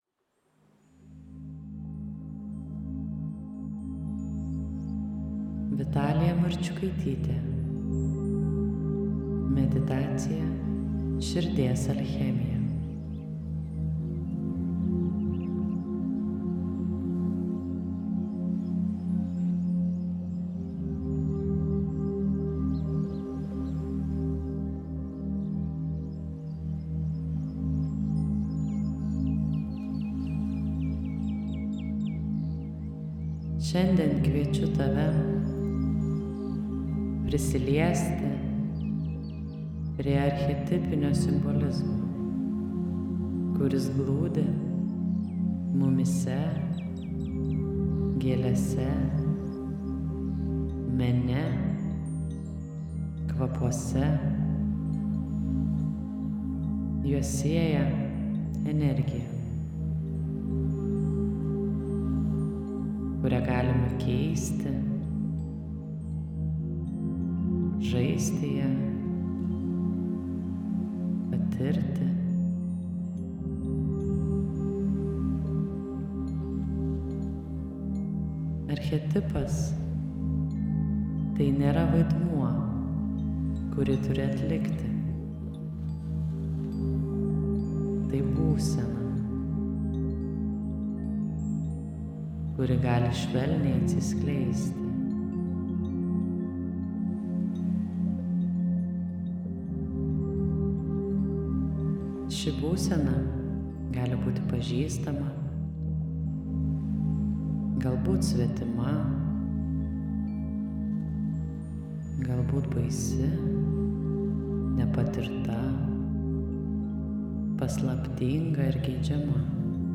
Gausos meditacija ir samprata
Ši švelni, pojūčiais grįsta meditacija kviečia tave į asmeninį ritualą, kuriame susijungia kvapas, archetipinė būsena ir sąmoningas buvimas.